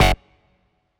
Futuristic Device Glitch (2).wav